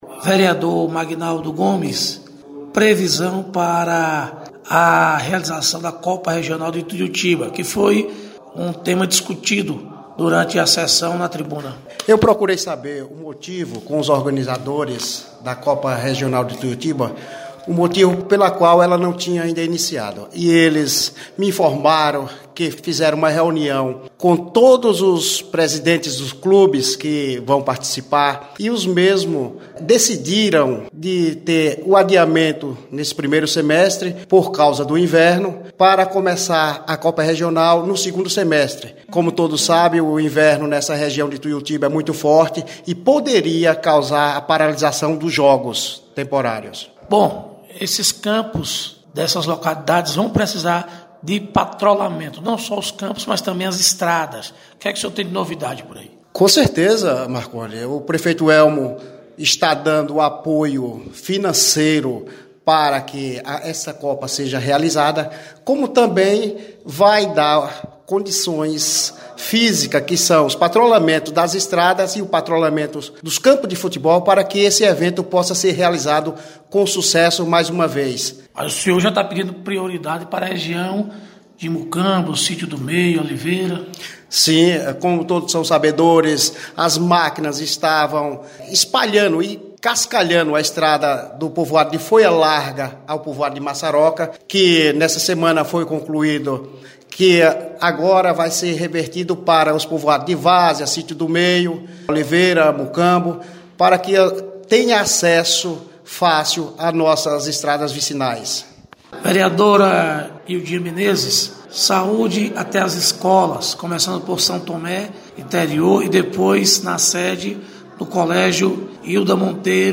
Reportagem: Vereadores de CF